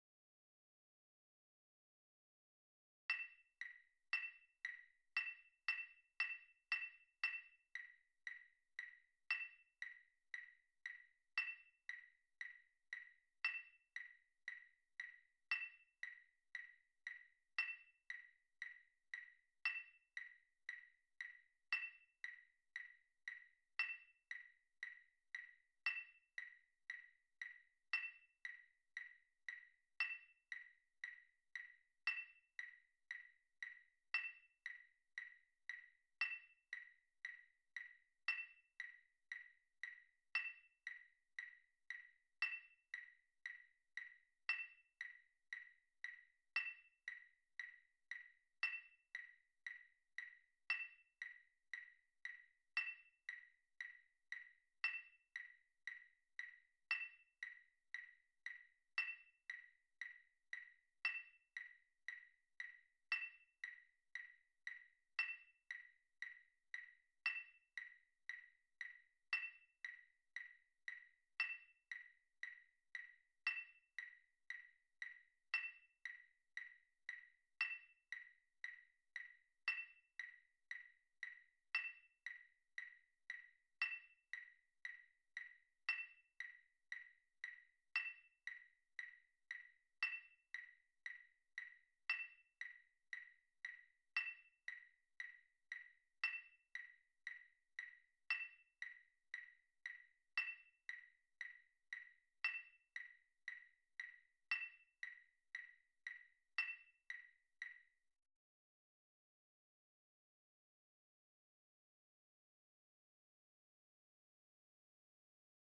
Concert Band
concert fanfare for young ensembles
Click Track 1 (Performance Tempo